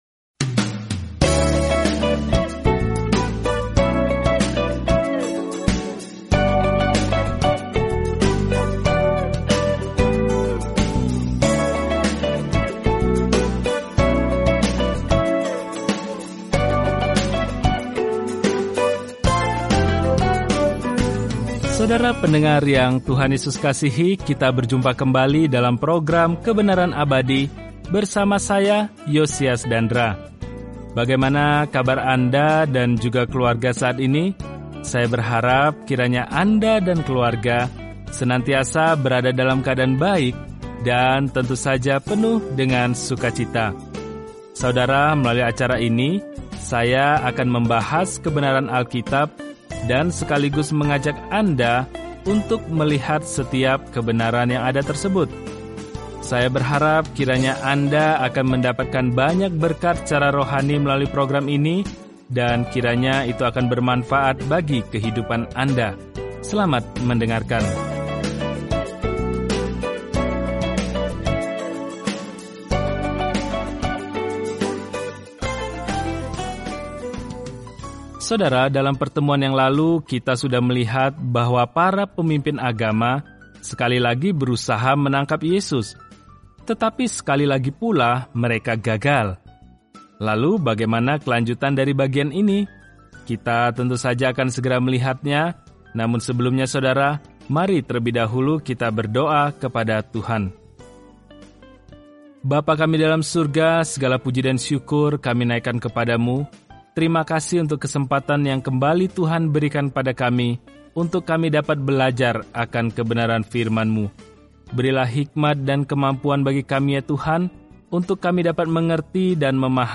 Firman Tuhan, Alkitab Yohanes 11:1-17 Hari 20 Mulai Rencana ini Hari 22 Tentang Rencana ini Kabar baik yang dijelaskan Yohanes unik dibandingkan Injil lainnya dan berfokus pada mengapa kita hendaknya percaya kepada Yesus Kristus dan bagaimana memiliki kehidupan dalam nama ini. Telusuri Yohanes setiap hari sambil mendengarkan pelajaran audio dan membaca ayat-ayat tertentu dari firman Tuhan.